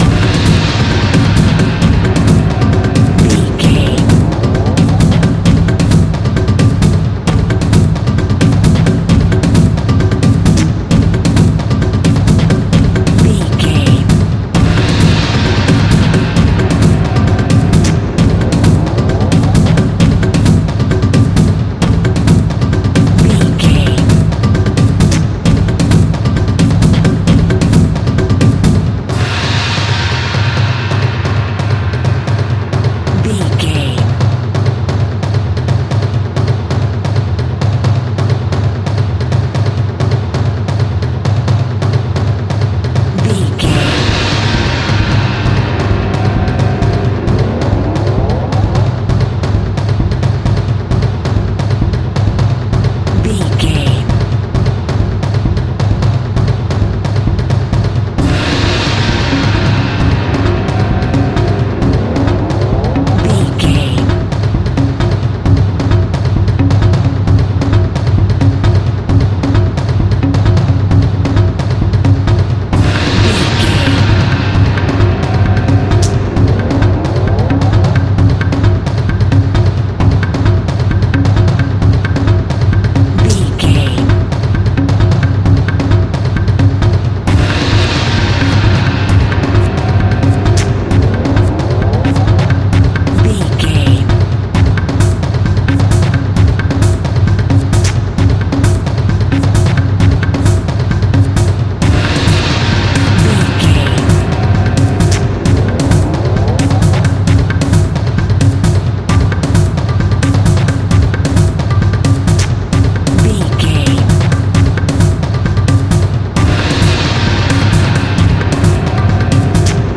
B♭
Fast
african drums
african percussion